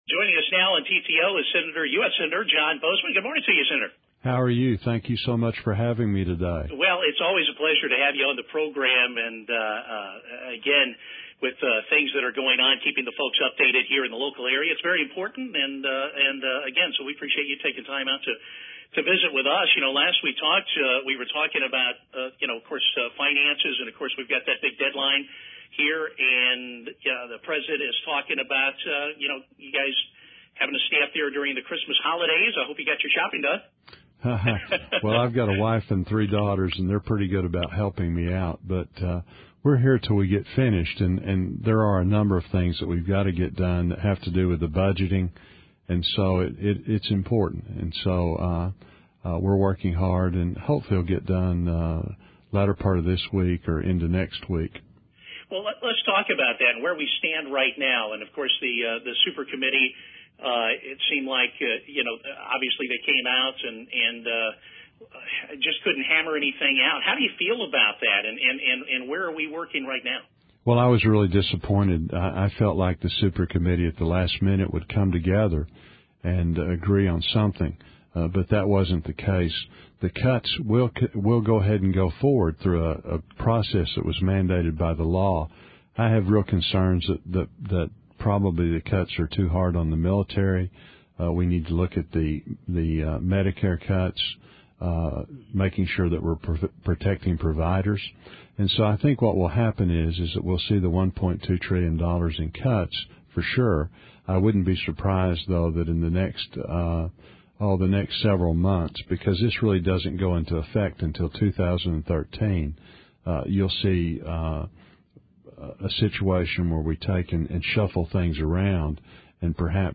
Interview With Fordyce Radio Station KBJT-KQEW